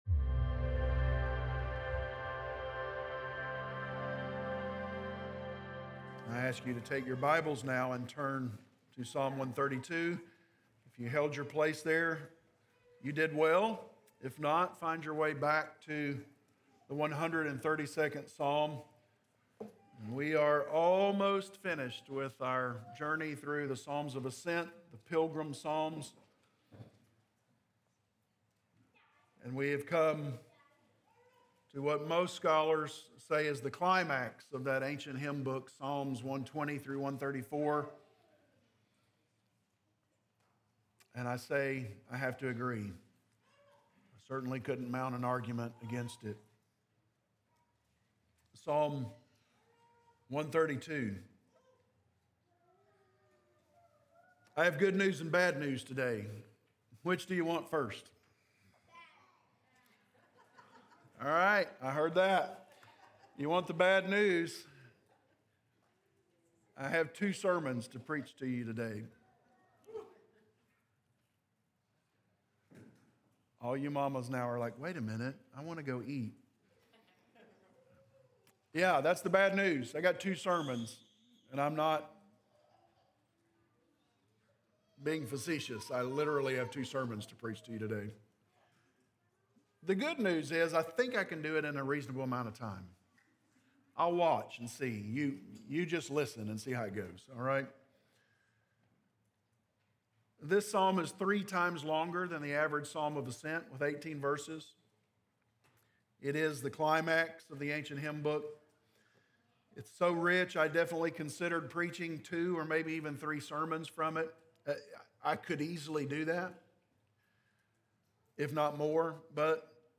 Sermons recorded during the Sunday morning service at Corydon Baptist Church in Corydon, Indiana